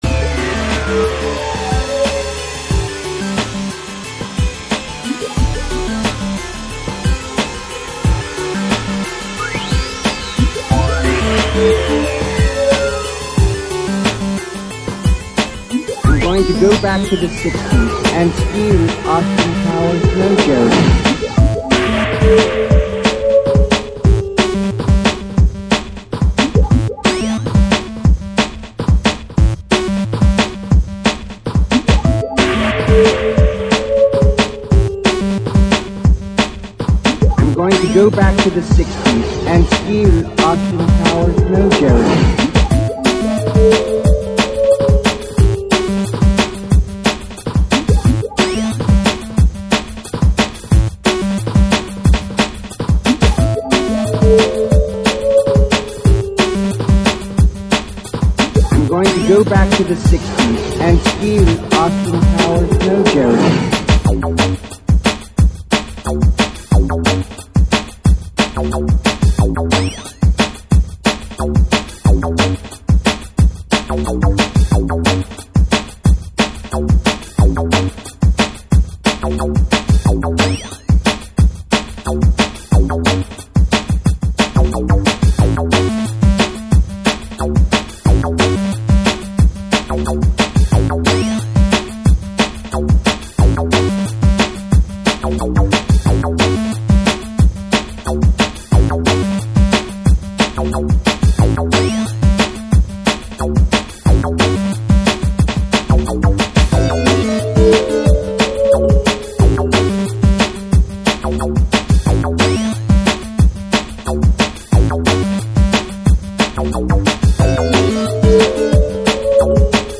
drum'n'bass
Here are MP3 files featuring our tracks and livesets: